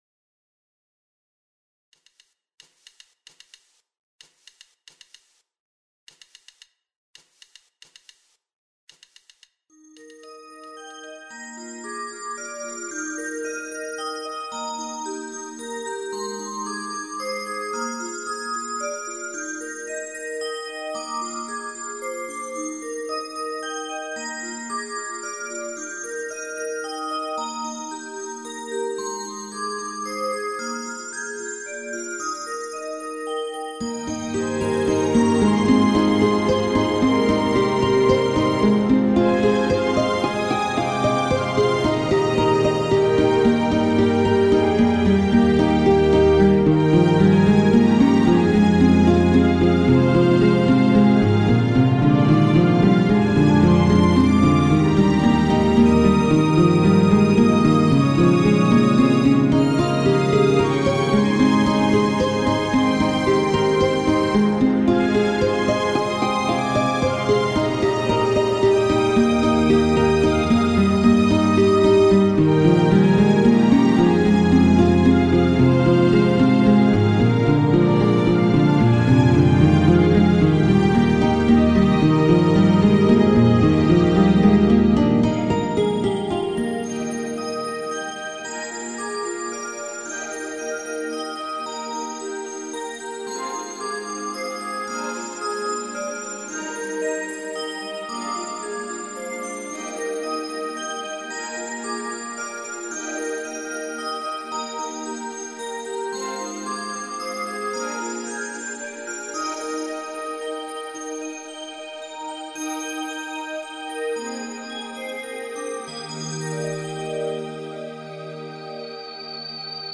BGM
エレクトロニカロング穏やか